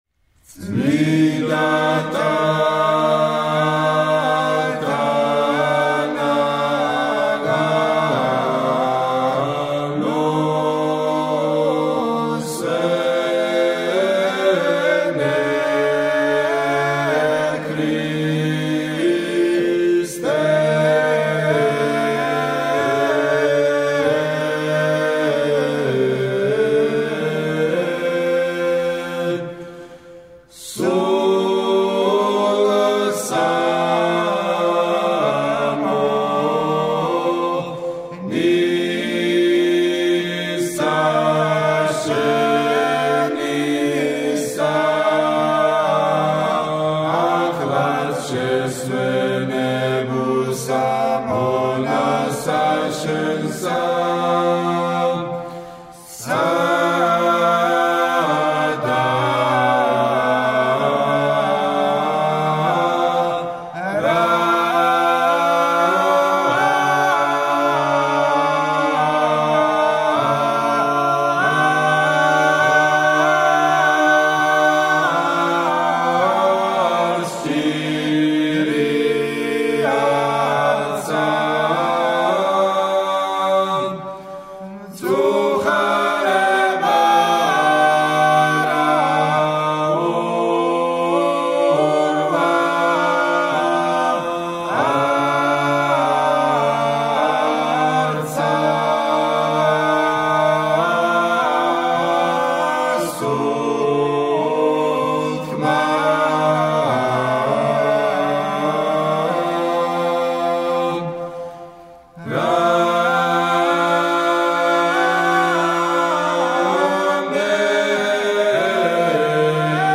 საგალობელი